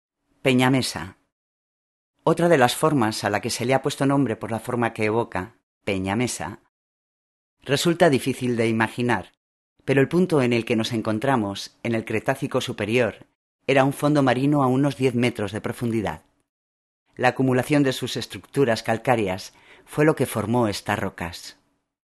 Locucion: